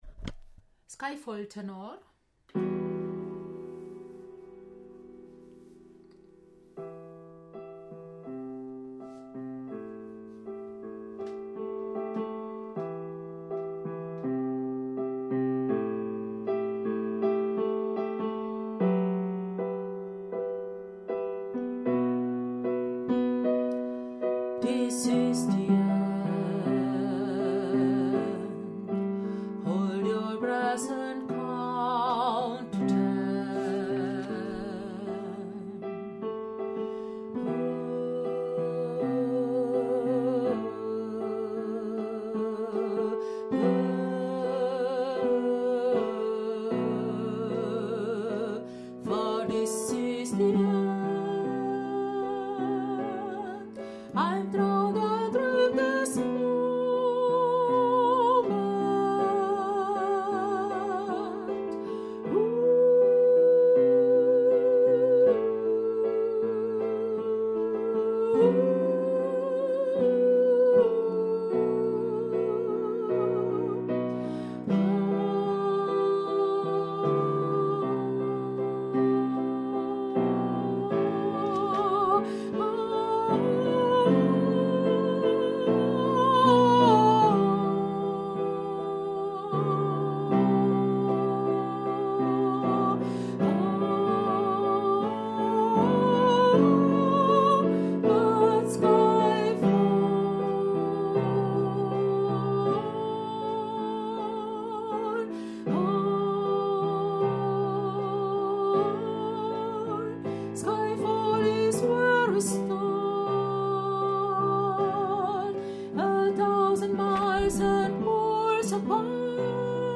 Skyfall-Tenor.mp3